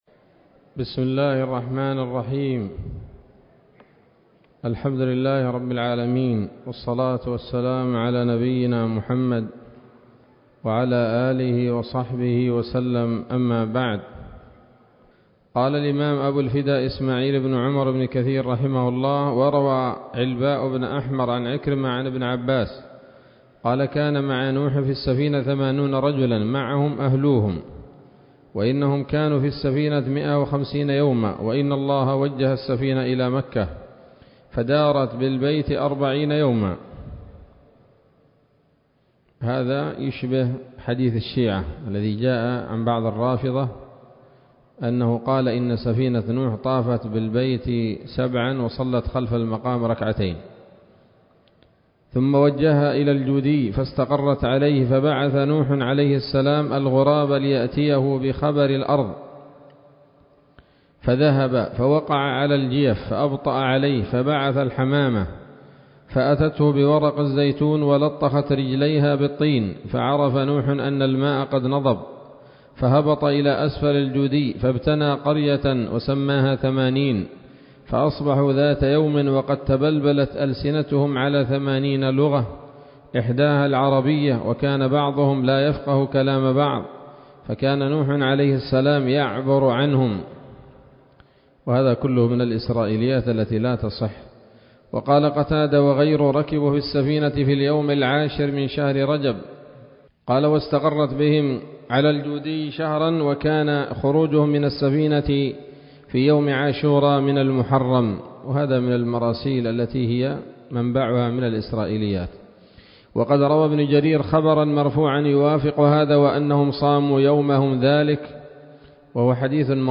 الدرس السابع والعشرون من قصص الأنبياء لابن كثير رحمه الله تعالى